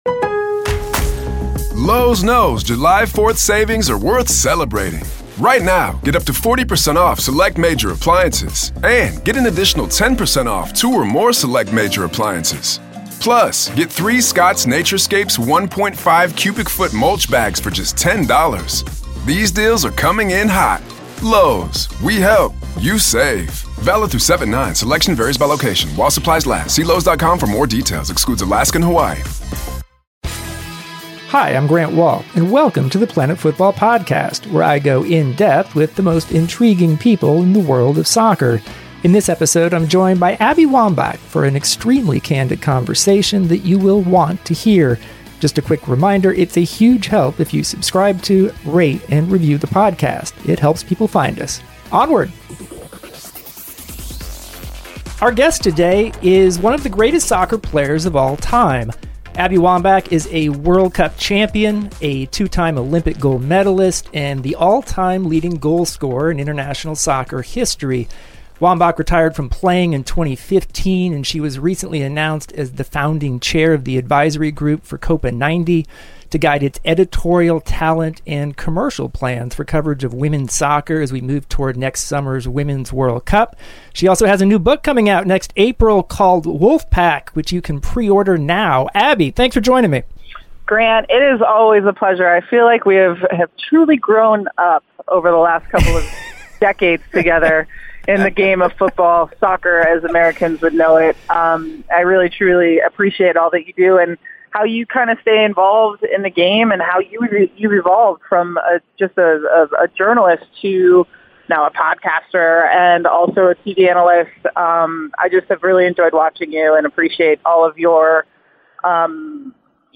Abby Wambach Interview